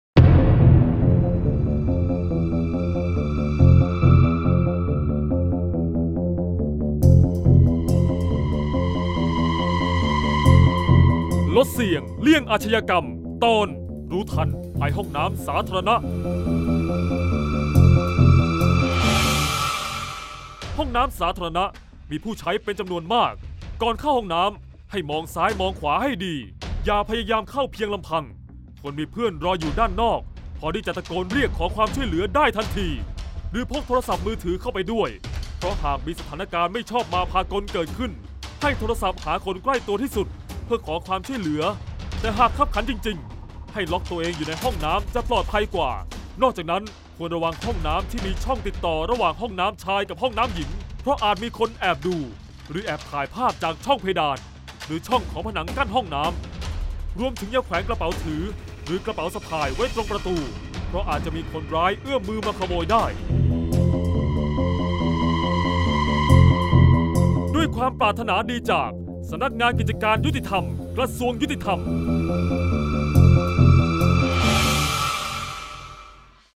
เสียงบรรยาย ลดเสี่ยงเลี่ยงอาชญากรรม 25-รู้ทันภัยห้องน้ำสาธารณะ